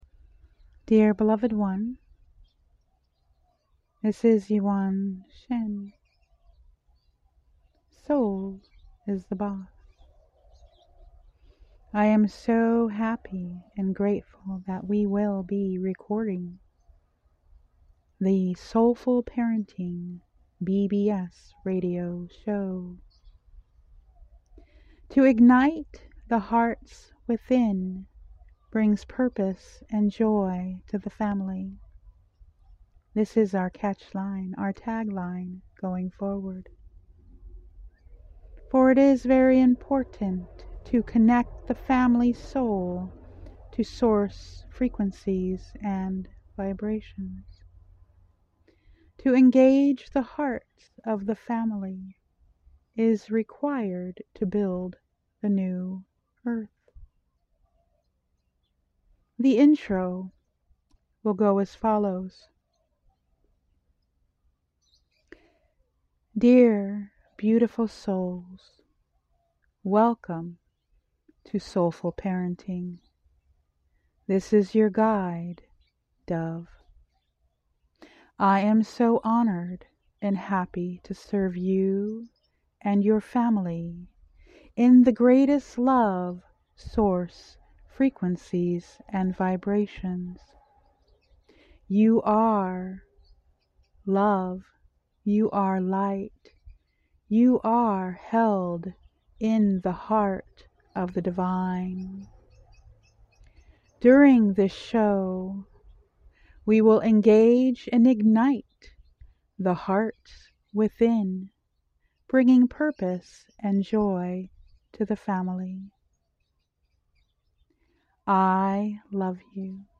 Talk Show Episode, Audio Podcast, Soulful Parenting and Igniting The Heart Within, Connecting Family Souls to Source Frequencies on , show guests , about Igniting The Heart Within,Connecting Family Souls to Source Frequencies,Source Frequencies,Soulful Parenting,Power of Tao Source Frequencies, categorized as Courses & Training,Alternative Health,Energy Healing,Love & Relationships,Mental Health,Self Help,Society and Culture,Spiritual,Meditation